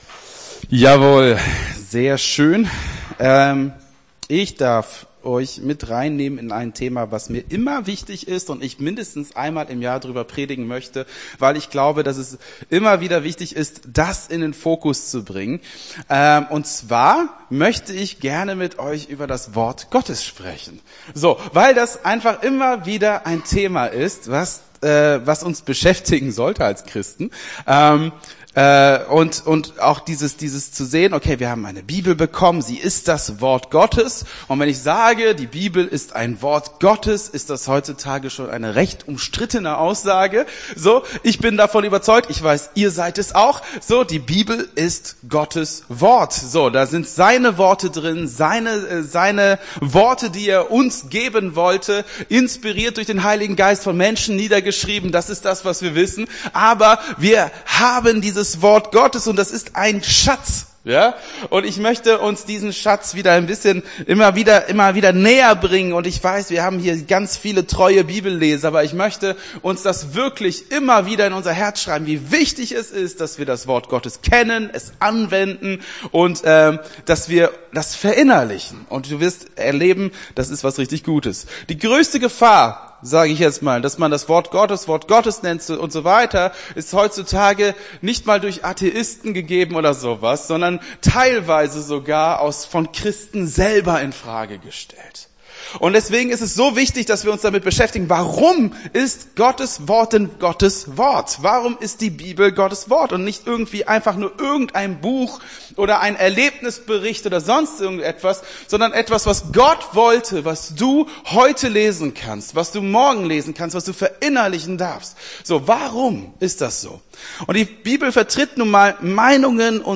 Gottesdienst 03.03.24 - FCG Hagen